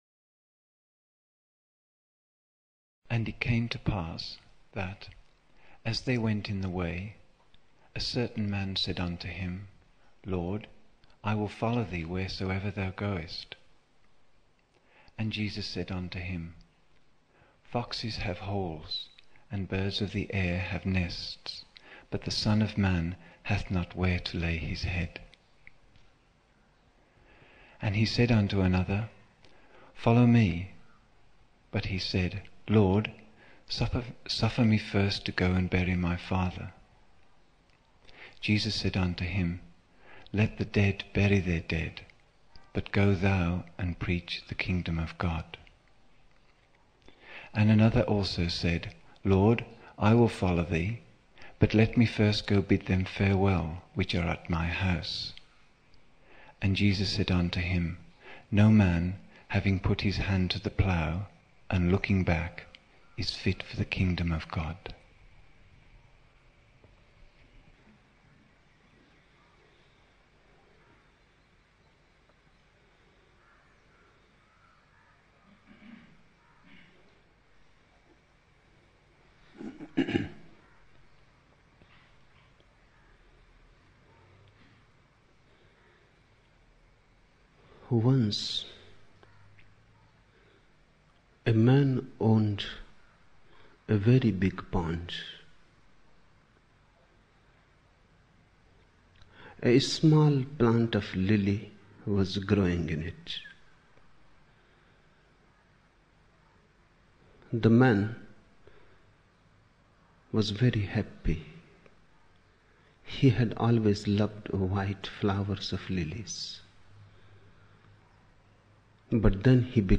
29 October 1975 morning in Buddha Hall, Poona, India